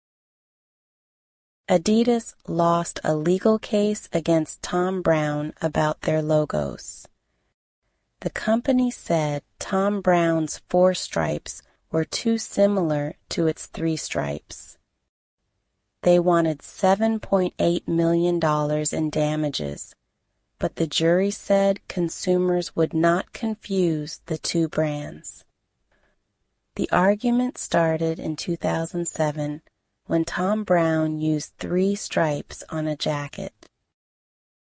[Easy News]
[Listening Homework]
Try other AI voices